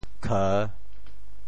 去 部首拼音 部首 厶 总笔划 5 部外笔划 3 普通话 qù 潮州发音 潮州 ke3 文 潮阳 ku3 文 ka3 白 澄海 ke3 文 揭阳 ke3 文 饶平 ke3 文 汕头 ke3 文 中文解释 潮州 ke3 文 对应普通话: qù ①离开所在的地方到别处，由自己一方到另一方，跟“来”相反：我～工厂 | 马上就～ | 给他～封信 | 已经～了一个电报。